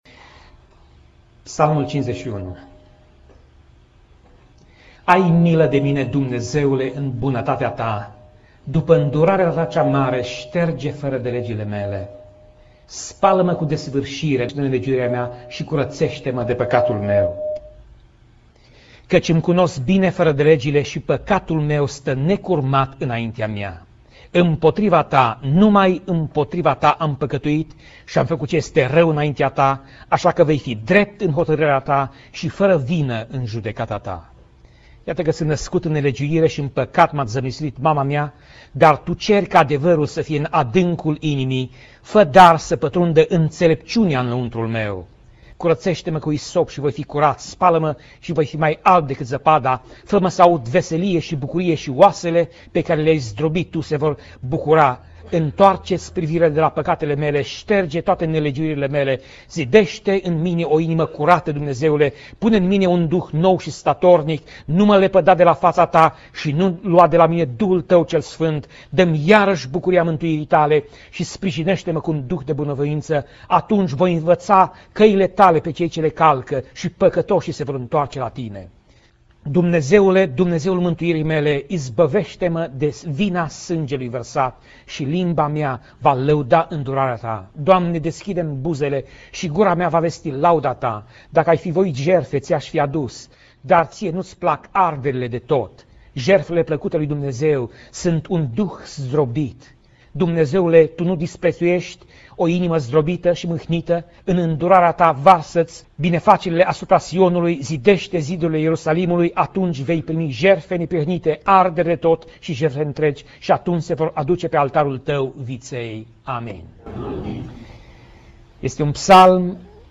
Pasaj Biblie: Psalmii 51:1 - Psalmii 51:19 Tip Mesaj: Predica